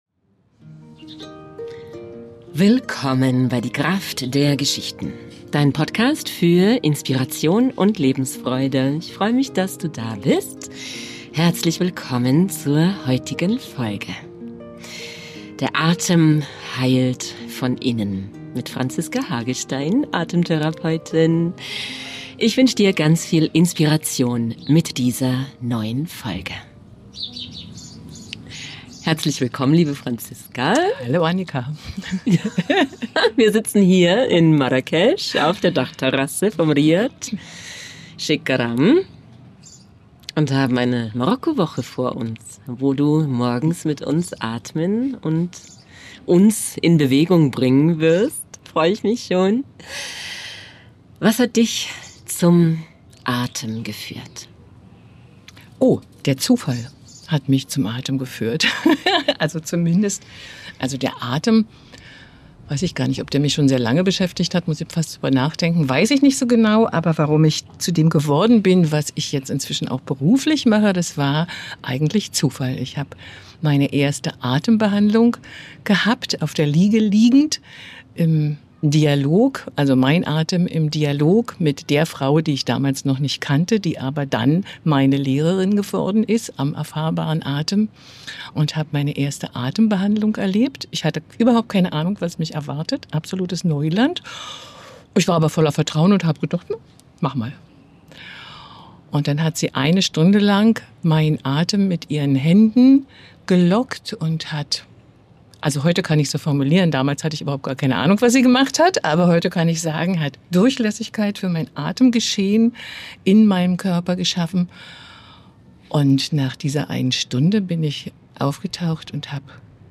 Zusammen grüßen wir Dich von der Dachterrasse in Marrakech.